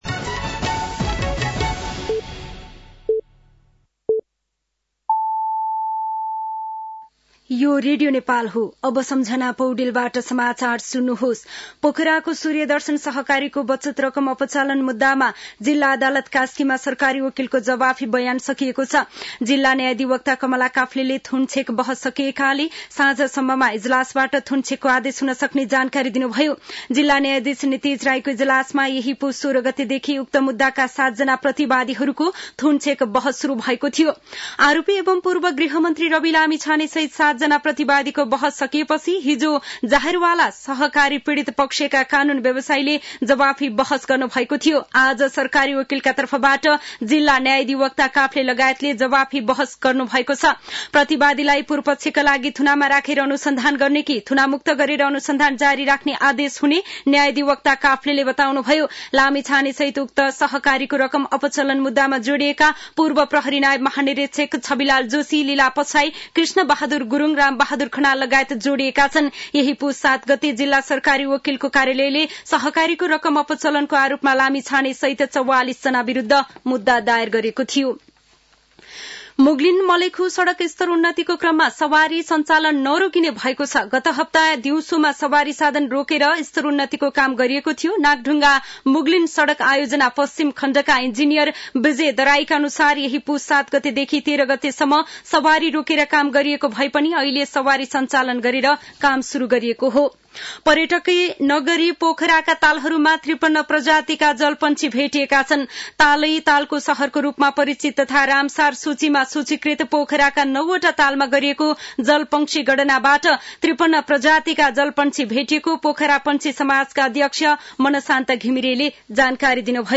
साँझ ५ बजेको नेपाली समाचार : २६ पुष , २०८१
5-pm-nepali-news-9-25.mp3